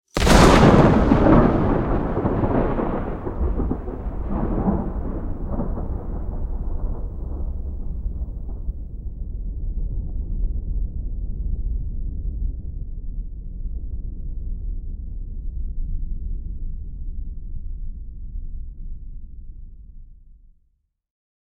thunder_15.ogg